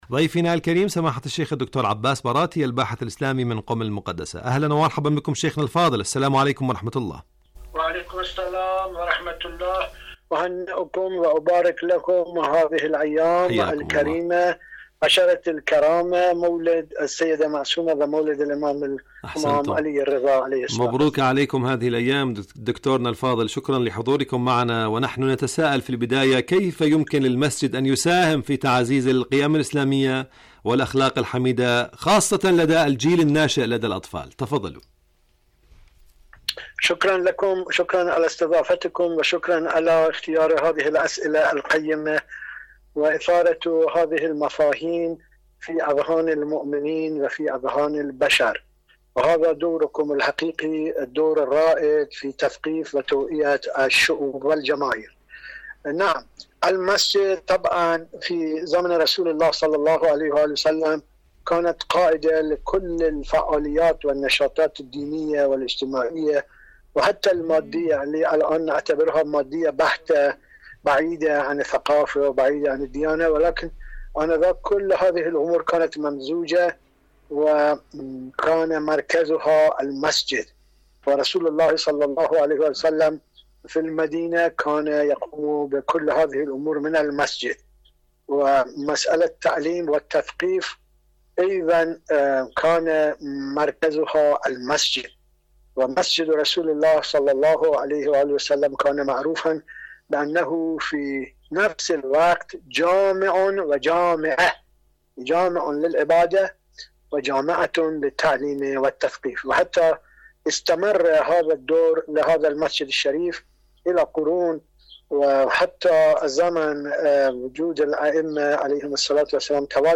مقابلات برامج إذاعة طهران العربية برنامج معكم على الهواء مقابلات إذاعية الأسرة الإسلامية الأسرة كيف نحبب المساجد لأطفالنا؟